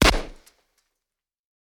bam.mp3